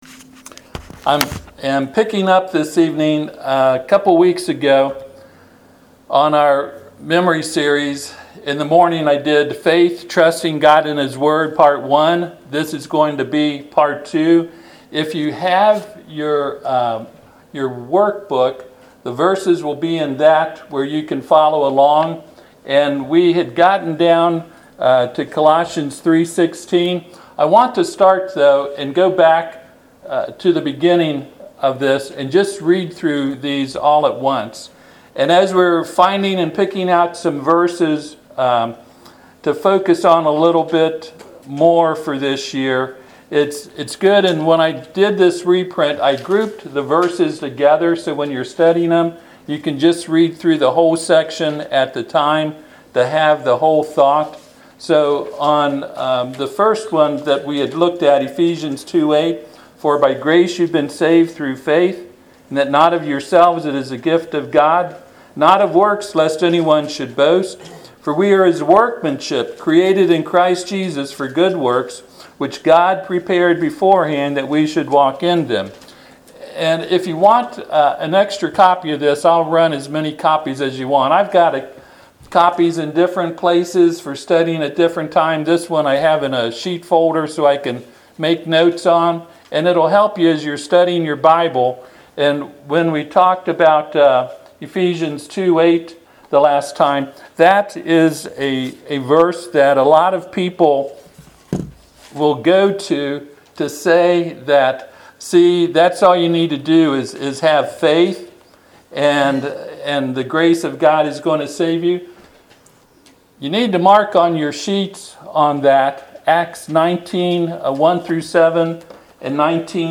Passage: Galatians 2:20 Service Type: Sunday PM